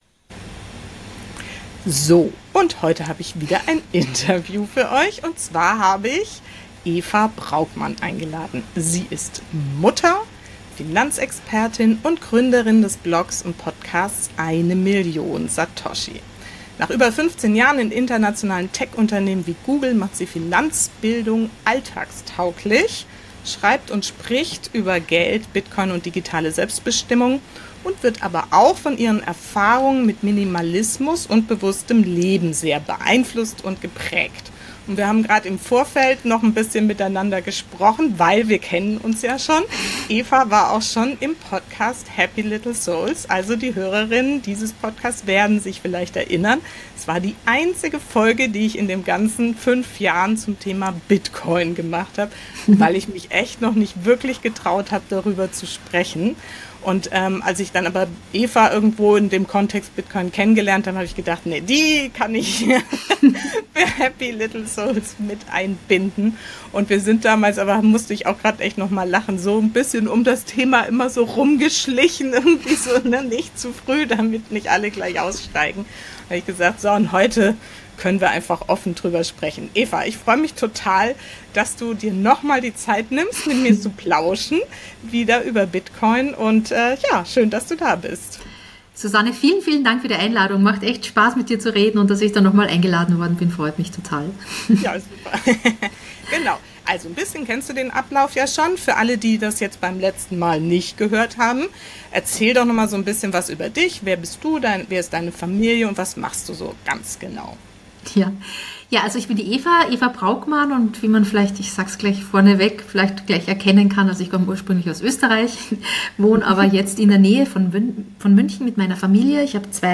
Es ist ein Gespräch über Klarheit, Entlastung, Bewusstsein und die Frage: Was brauchen wir als Familie eigentlich wirklich – und was dürfen wir loslassen?